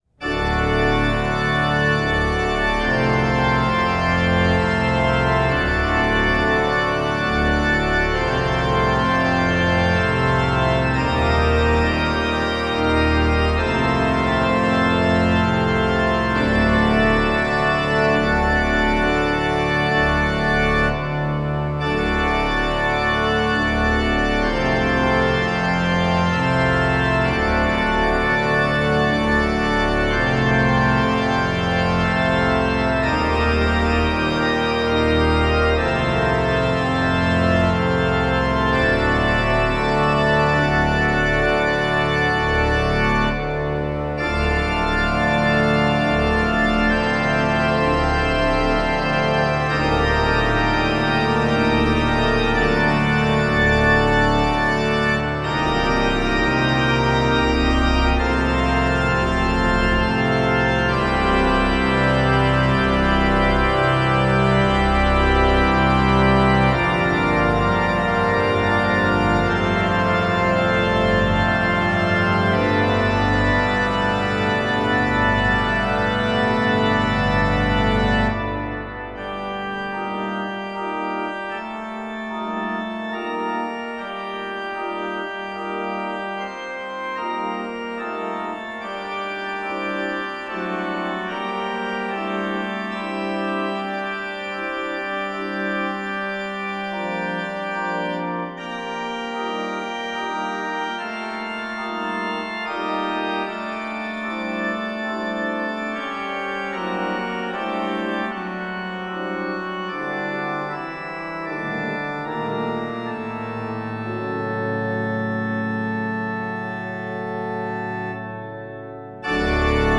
It is a grand and majestic festive prelude.